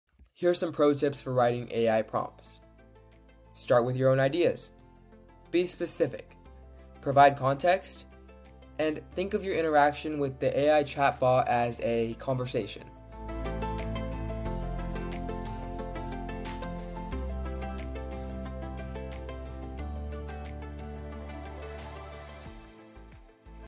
He is a young man wearing a black t-shirt and glasses.